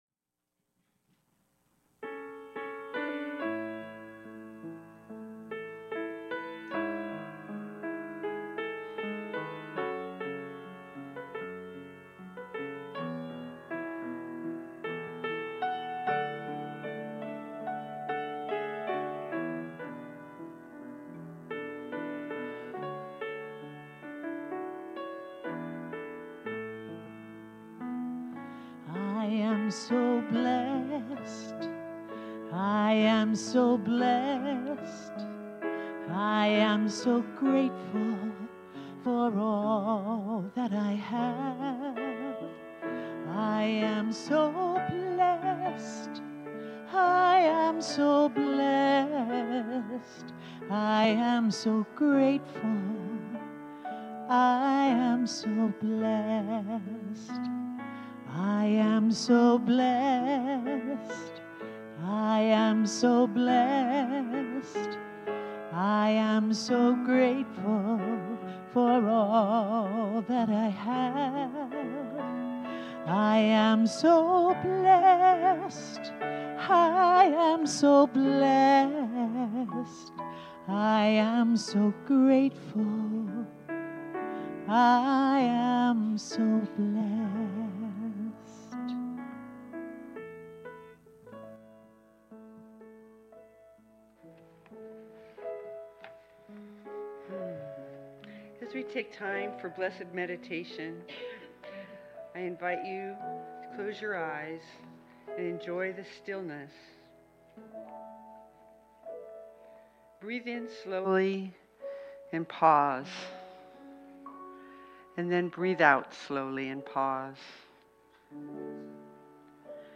The audio recording (below the video clip) only includes the Meditation, Message, and Featured Song.